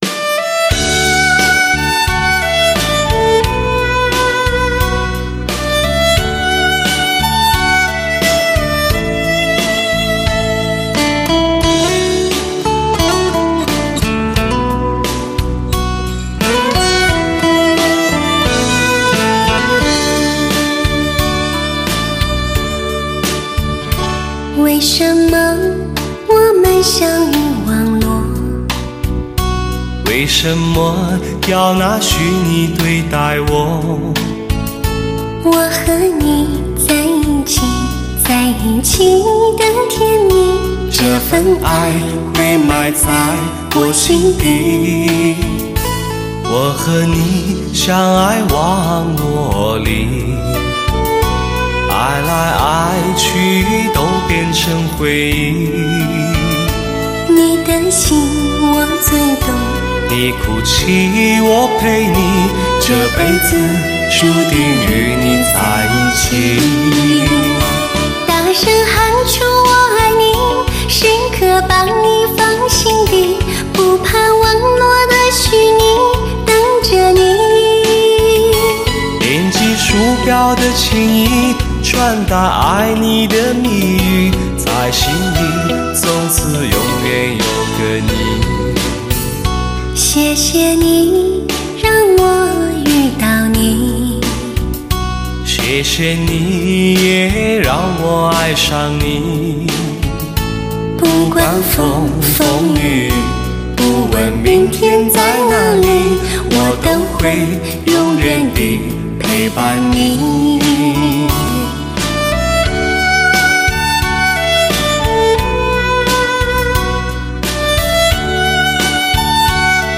至尊之爱对唱情歌 醇味发烧男女全新演绎。